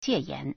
解严 (解嚴) jiěyán
jie3yan2.mp3